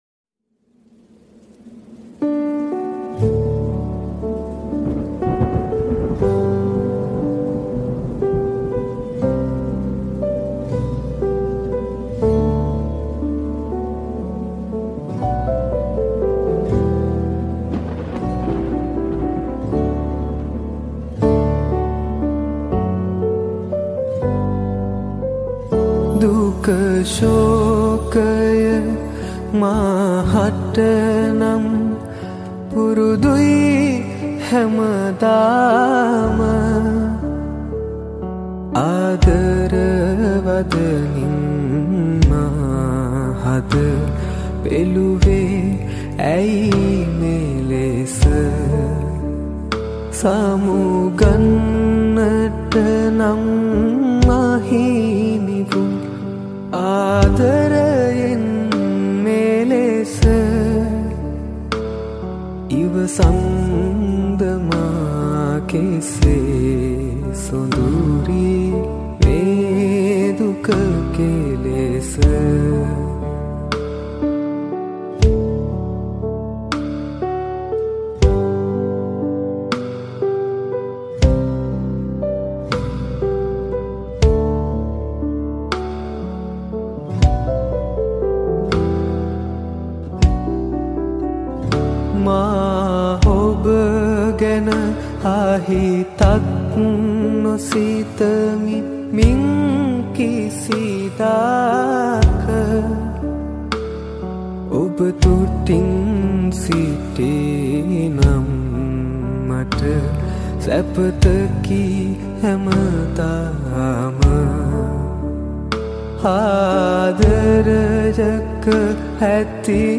Cover Song EdM mix